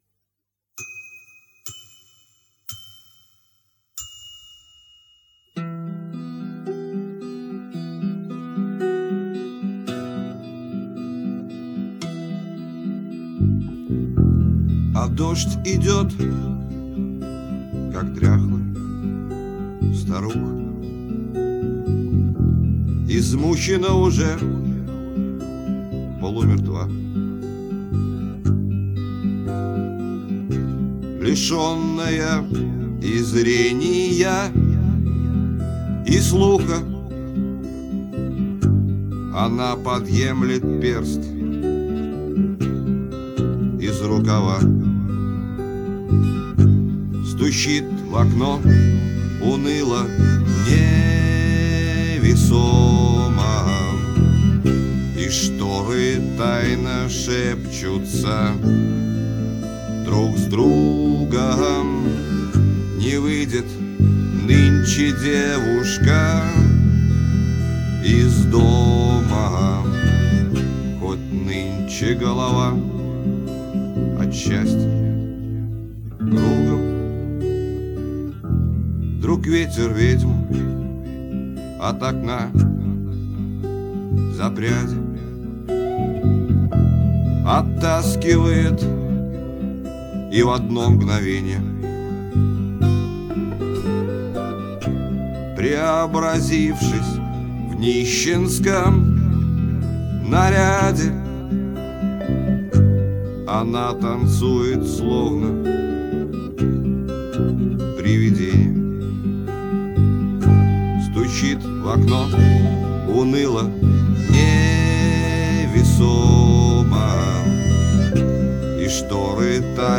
(микрофон висел на кипятильнике :)